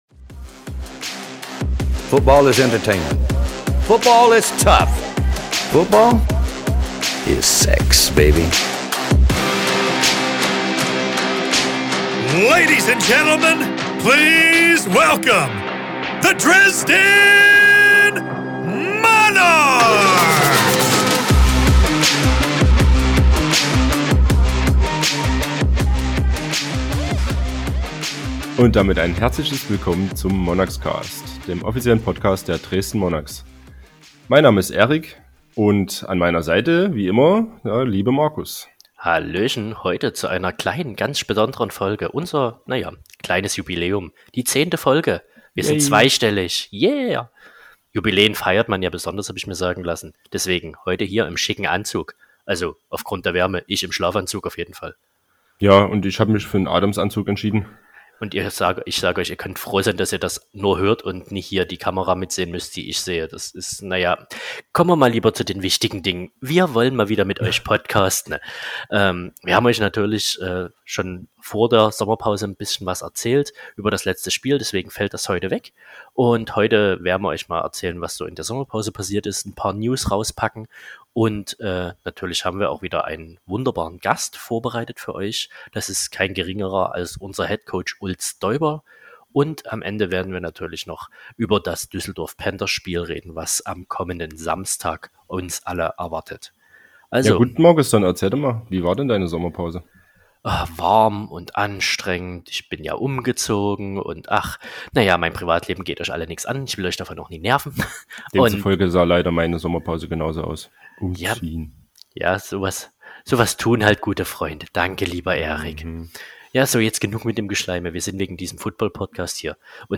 Interviewgast